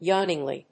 アクセント・音節yáwn・ing・ly